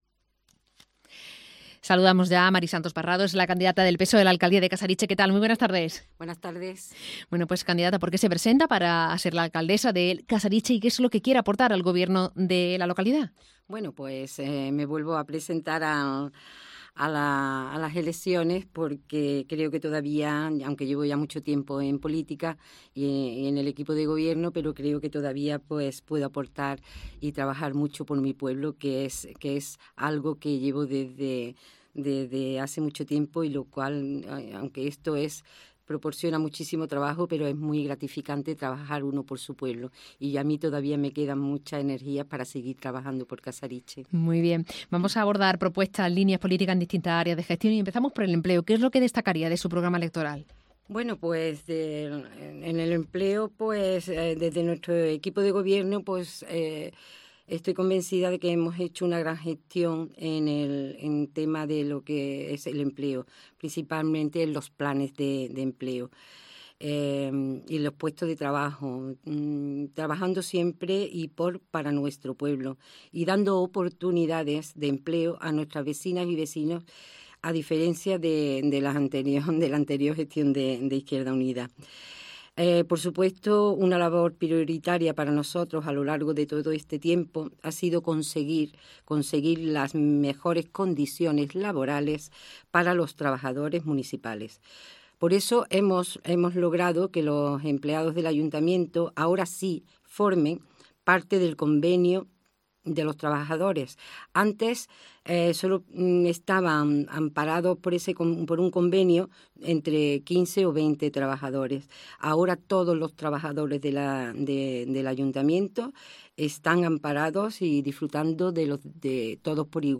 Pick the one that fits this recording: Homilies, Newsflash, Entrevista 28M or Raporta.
Entrevista 28M